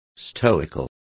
Complete with pronunciation of the translation of stoical.